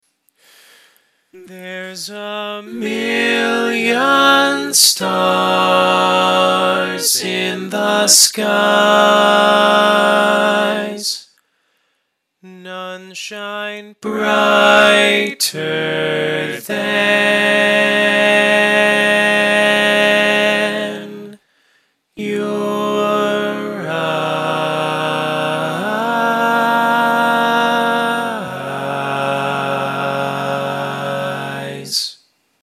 Key written in: G♭ Major
Type: Barbershop